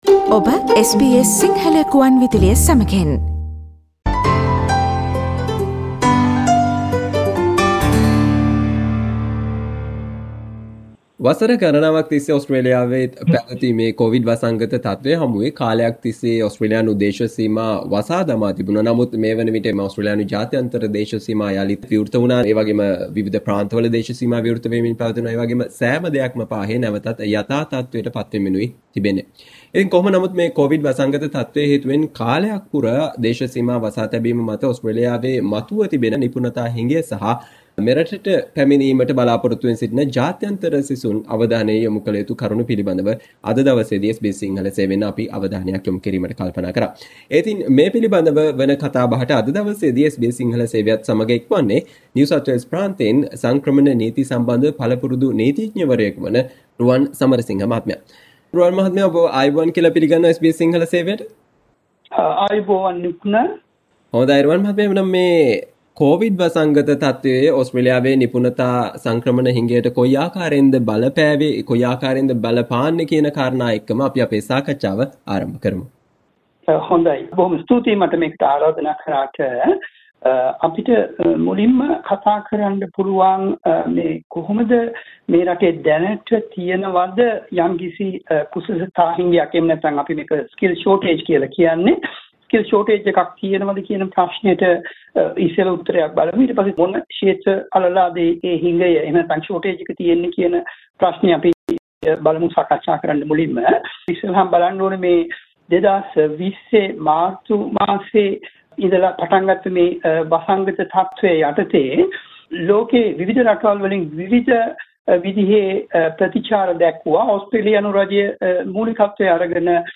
කොවිඩ් වසංගතයේ ප්‍රතිපලයක් මත ඔස්ට්‍රේලියාව තුල මේ වනවිට පවතින රැකියා අවස්ථා සම්බන්ධයෙන් සහ ඔස්ට්‍රේලියාවට ජාත්‍යන්තර සිසුන් වශයෙන් පැමිණීමේදී අවධානයක් යොමු කලයුතු මූලික කරුණු පිළිබඳව SBS සිංහල සේවය සිදු කල සාකච්චාවට සවන්දෙන්න